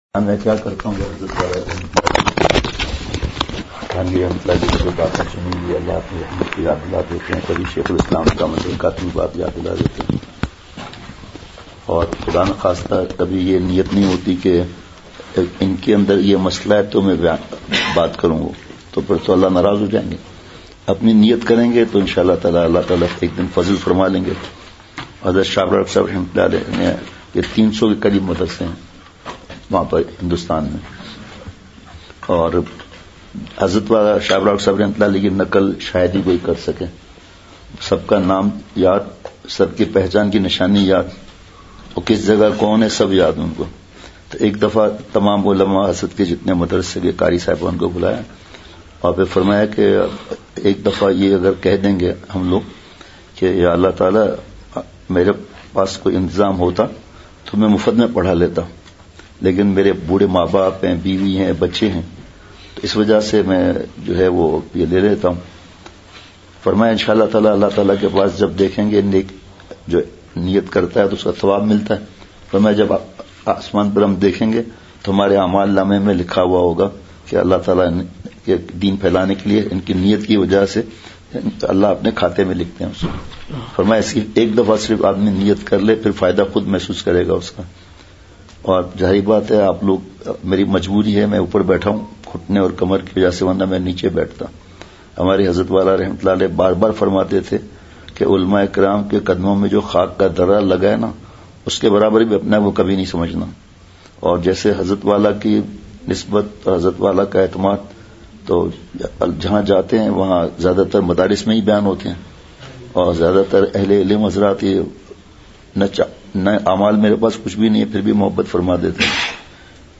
بمقام۔ جامعہ تعلیم القرآن تختہ بند سوات
صبح ساڑے گیارہ بجے اساتذہ کرام میں بیان بیان میں اپنی نیت ہونی چاہیے۔
اختتام پر درد بھری دعا ہوئی۔